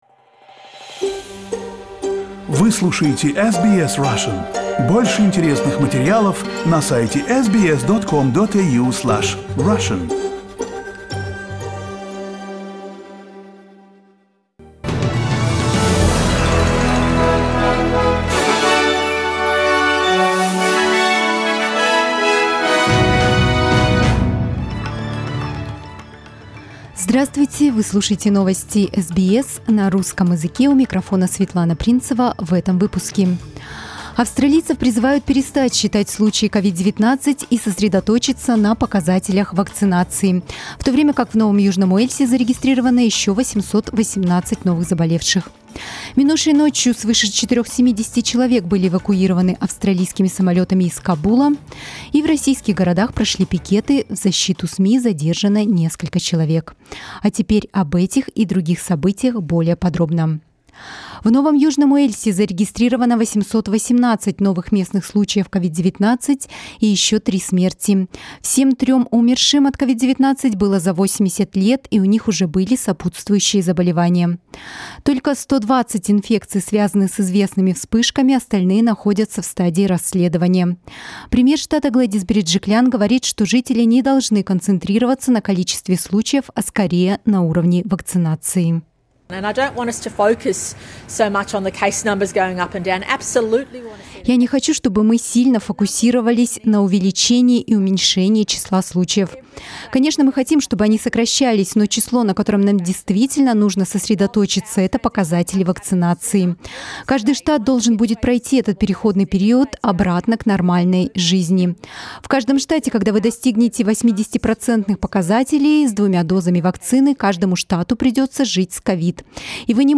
Новости SBS на русском языке - 23.08